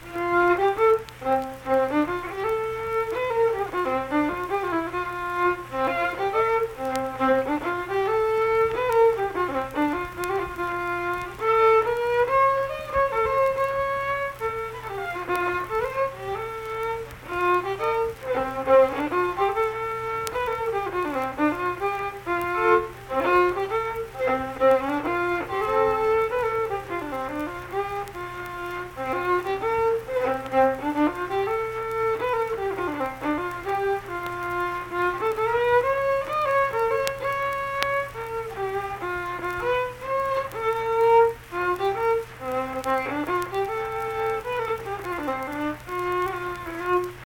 Unaccompanied vocal and fiddle music
Instrumental Music
Fiddle
Pleasants County (W. Va.), Saint Marys (W. Va.)